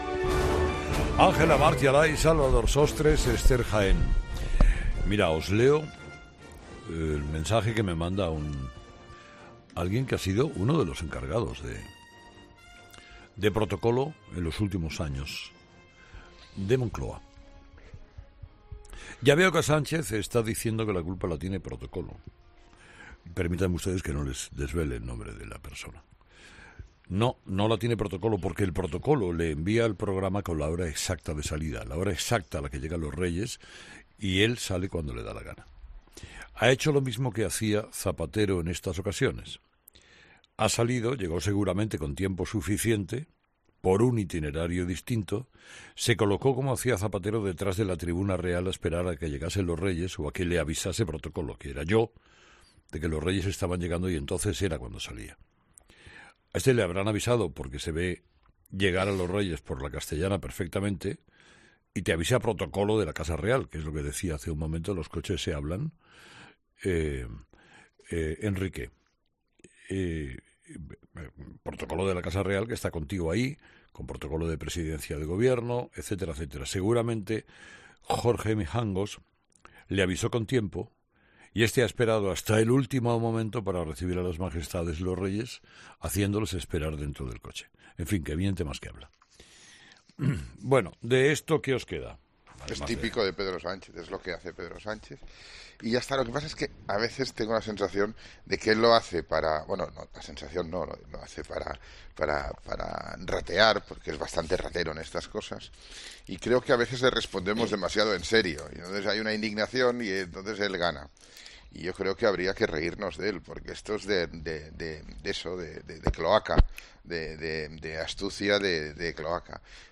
Carlos Herrera lee el mensaje de un extrabajador de Moncloa en directo: "Sánchez sale cuando le da la gana"